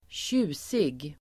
Uttal: [²tj'u:sig]